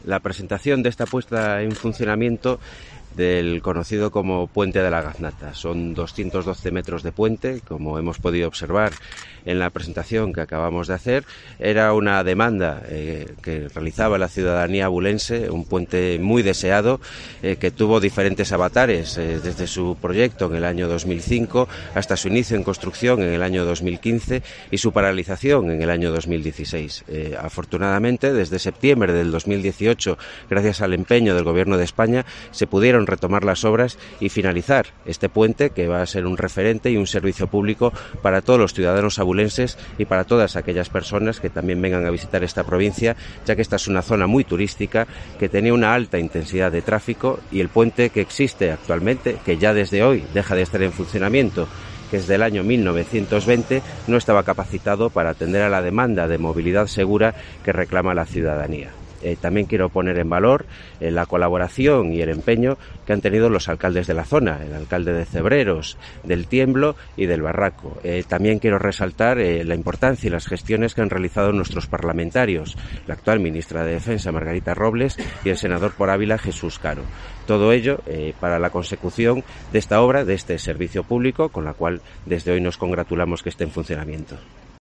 Esta mañana, el Subdelegado del Gobierno en Ávila, Arturo Barral acudía a la puesta en marcha del puente junto con los alcaldes de la zona y ha recordado que este proyecto estaba sobre la mesa desde 2005. (Audio)